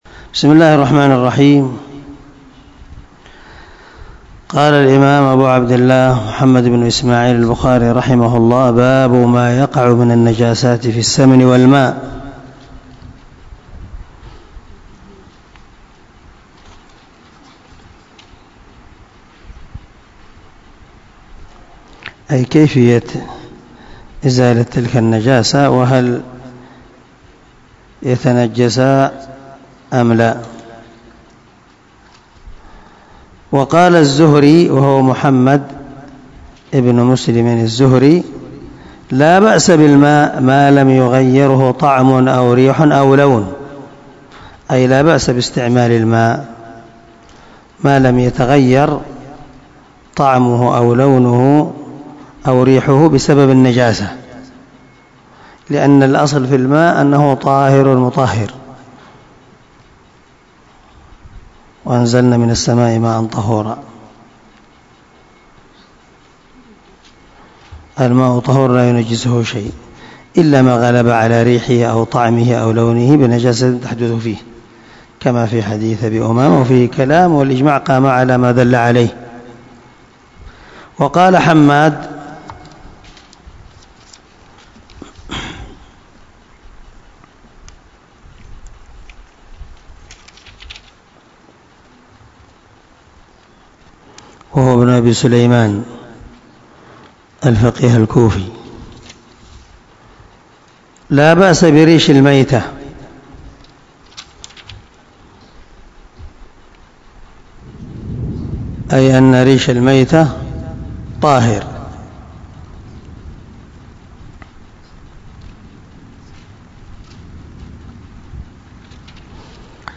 197الدرس 73 من شرح كتاب الوضوء حديث رقم ( 235 - 236 ) من صحيح البخاري